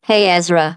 synthetic-wakewords
ovos-tts-plugin-deepponies_GLaDOS_en.wav